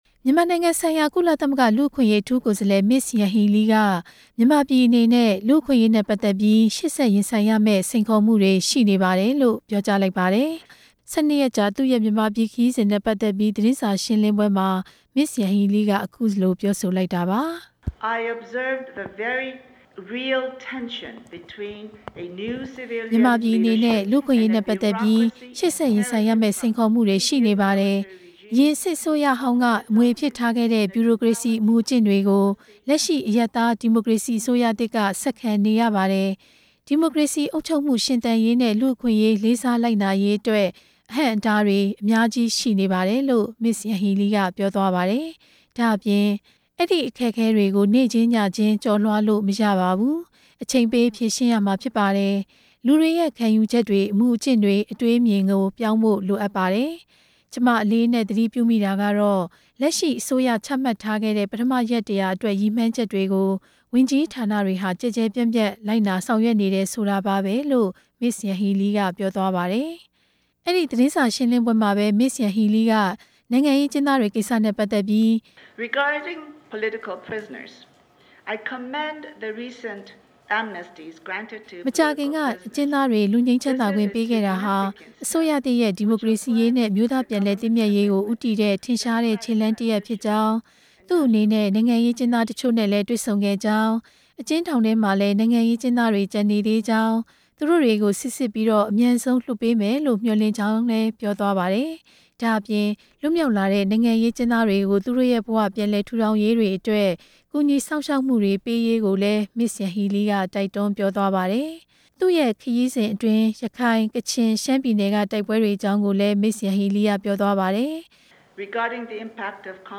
မစ္စယန်ဟီးလီရဲ့ သတင်းစာ ရှင်းလင်းပွဲ တင်ပြချက်
မြန်မာပြည်အနေနဲ့ လူ့အခွင့်အရေးနဲ့ ပတ်သက်ပြီး ရှေ့ဆက်ရင်ဆိုင်ရမယ့် စိန်ခေါ်မှုတွေ ရှိနေပါတယ်လို့ ကုလသမဂ္ဂလူ့အခွင့်အရေး အထူးကိုယ်စားလှယ် မစ္စယန်ဟီးလီက ပြောကြားလိုက်ပါတယ်။ သူ့ရဲ့ မြန်မာနိုင်ငံ ၁၂ ရက်ကြာ ခရီးစဉ်အပြီး ရန်ကုန်မြို့မှာ မနေ့က ပြုလုပ်တဲ့သတင်းစာရှင်းလင်းပွဲမှာပြောကြားခဲ့တာပါ။